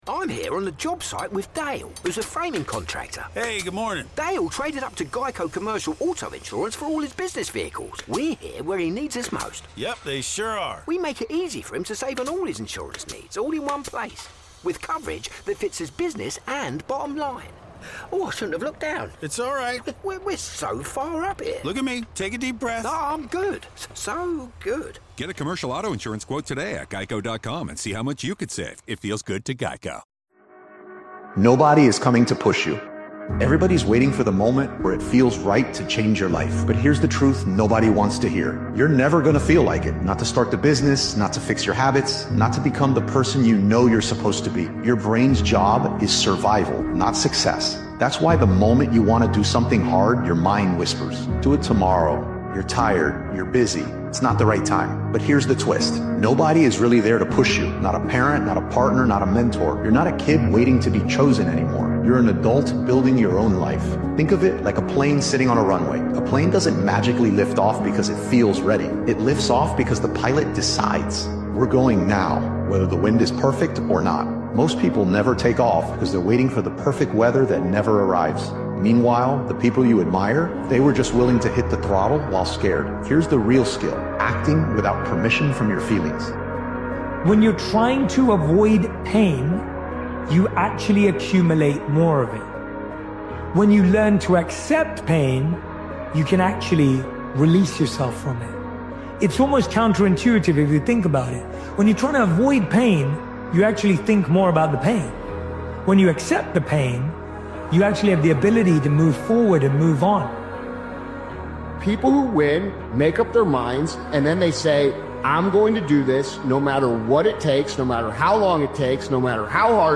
CHANGE YOUR LIFE ONCE AND FOR ALL – Powerful Motivational Speech is a no-turning-back motivational episode created and edited by Daily Motivations.